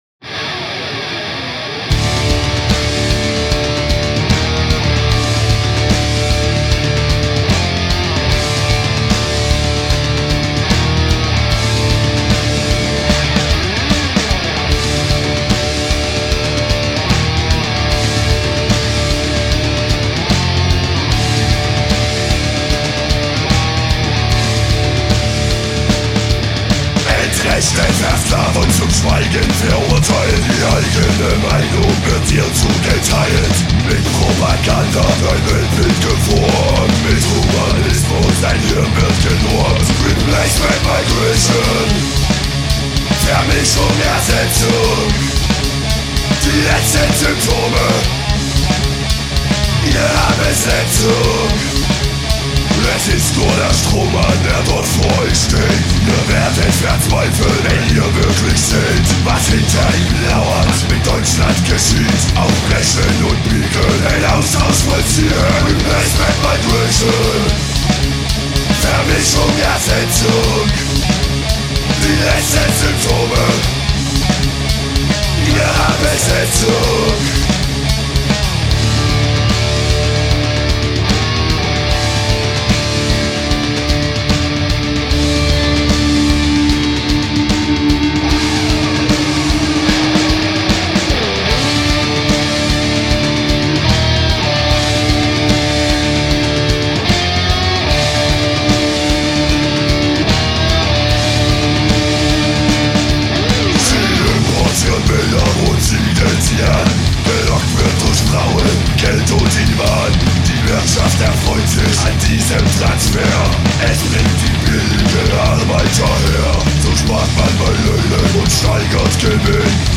Politmetals